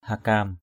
hakam.mp3